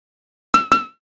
fire-1.ogg.mp3